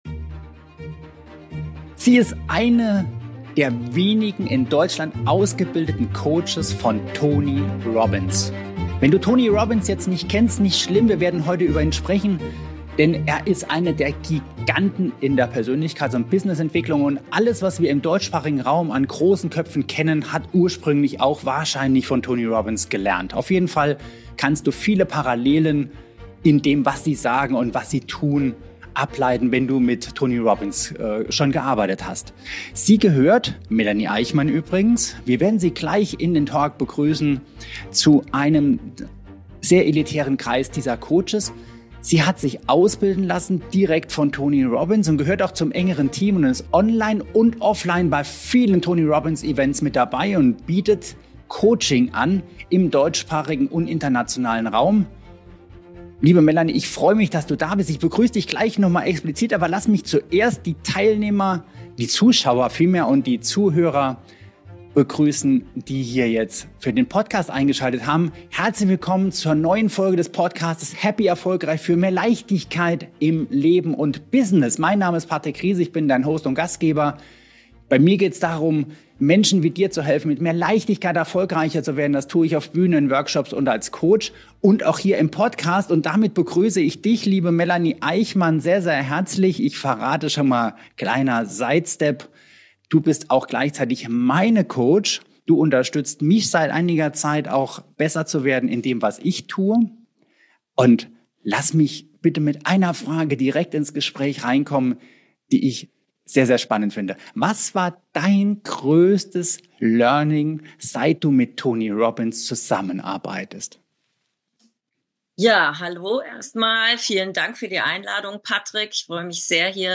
Ein inspirierendes Gespräch für alle, die mehr erreichen und dabei authentisch bleiben wollen.